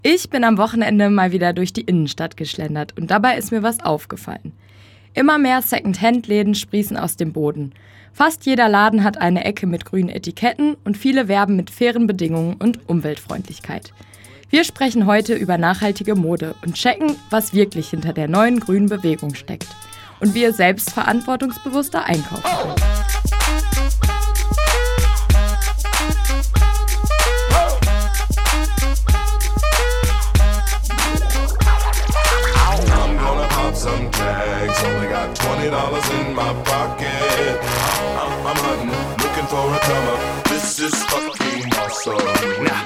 Teaser_422_Hamburg_2021_2_Nachhaltige_Mode_192kbit.mp3